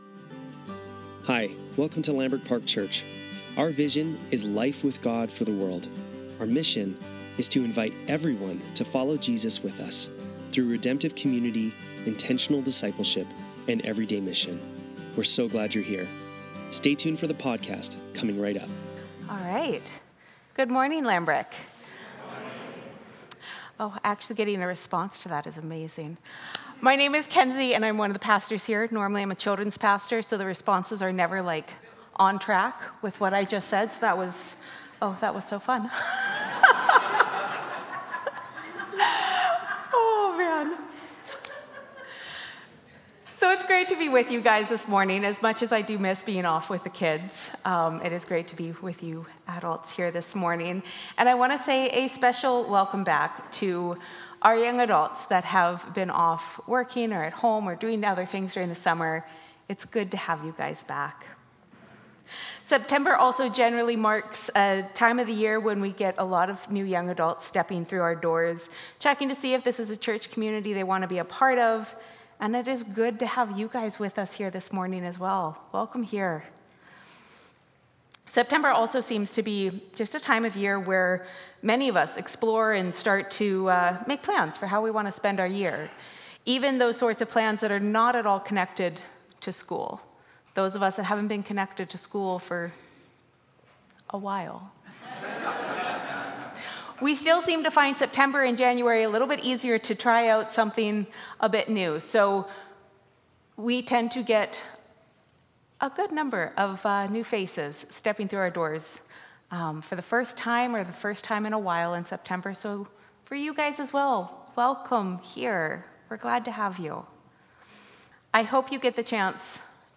Sermons | Lambrick Park Church
Sunday Service - September 8, 2024